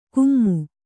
♪ kummu